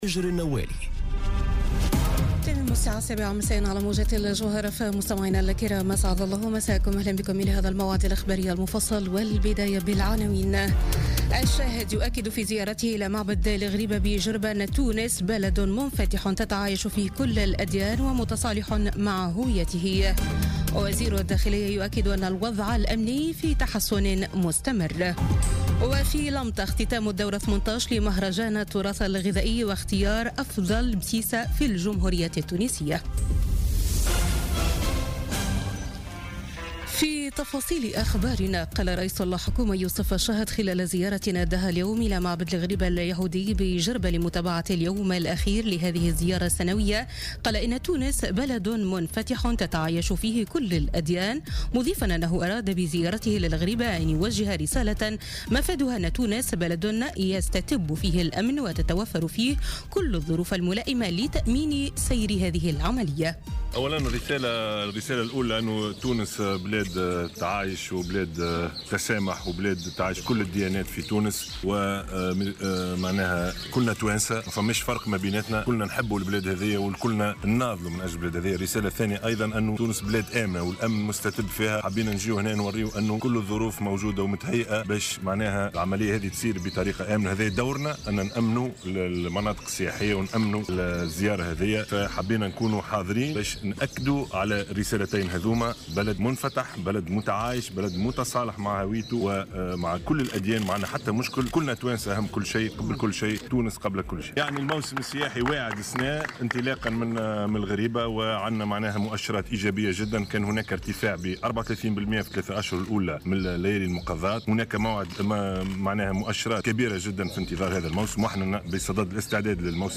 نشرة أخبار السابعة مساء ليوم الأحد 14 ماي 2017